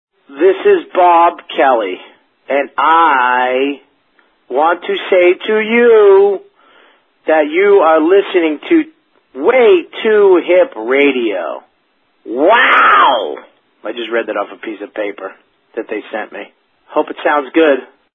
LINER
Category: Radio   Right: Personal